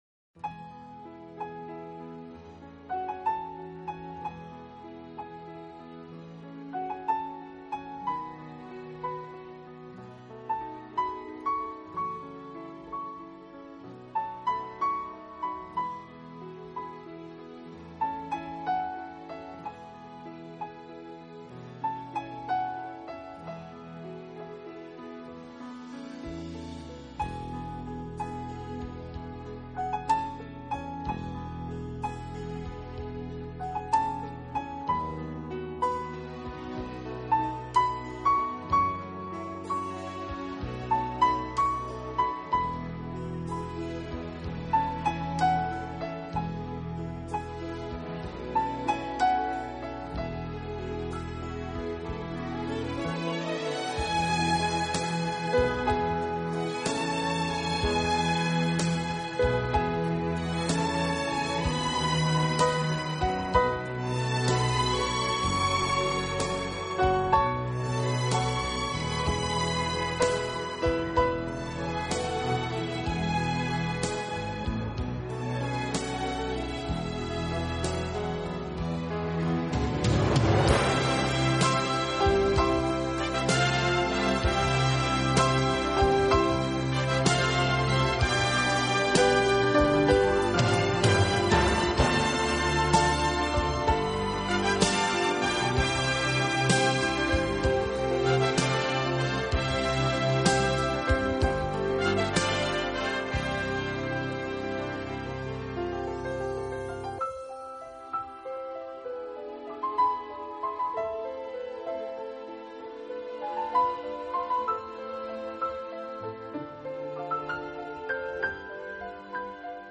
音乐风格：Easy Listening